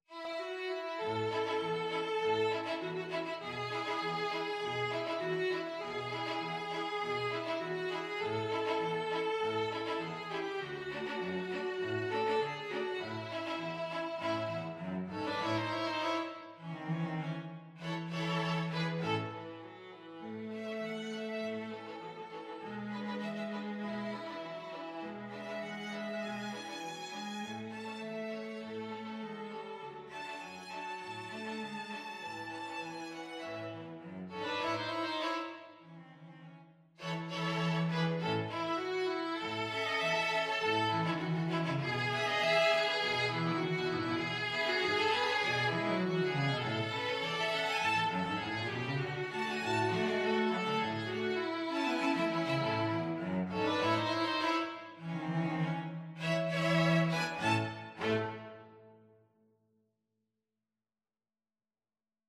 Childrens Childrens String Quartet Sheet Music Down By The Bay
Free Sheet music for String Quartet
Violin 1Violin 2ViolaCello
2/2 (View more 2/2 Music)
A major (Sounding Pitch) (View more A major Music for String Quartet )
Quick two in a bar = c.100
String Quartet  (View more Intermediate String Quartet Music)
Traditional (View more Traditional String Quartet Music)